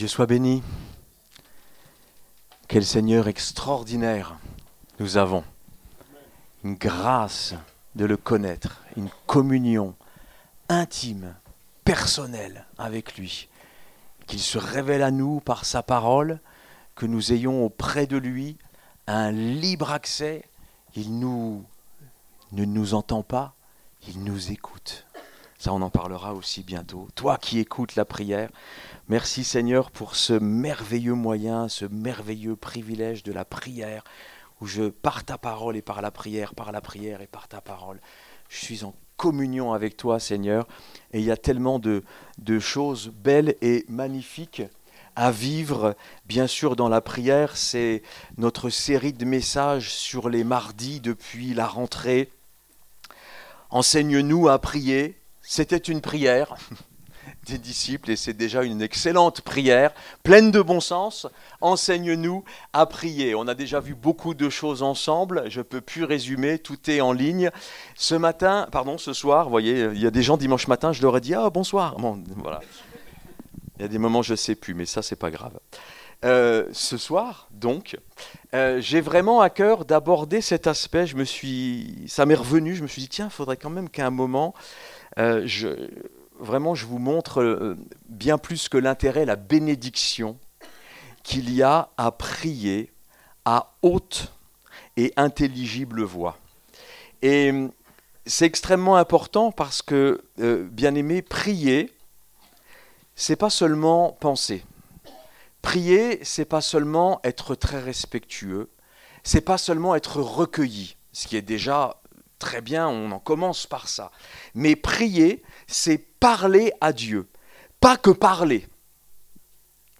11 novembre 2025 Prier à haute et intélligible voix Prédicateur